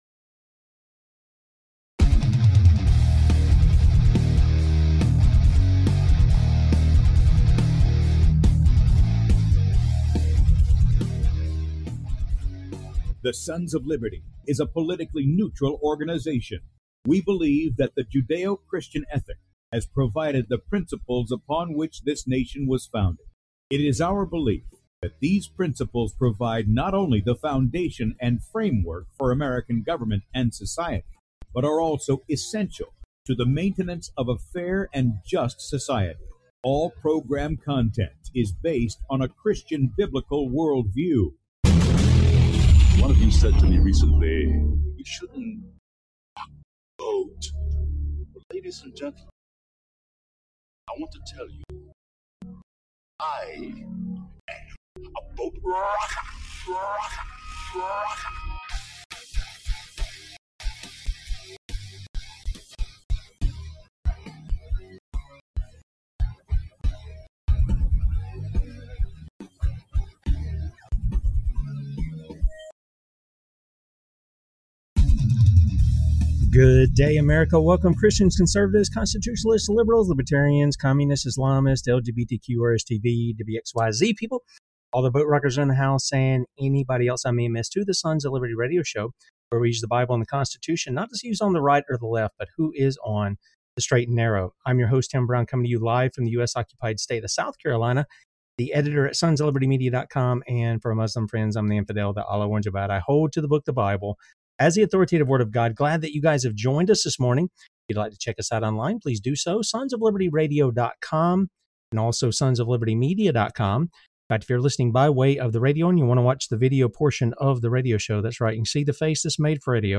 ➡ The Sons of Liberty is a religious-political show that promotes Judeo-Christian ethics, American values, and health freedom.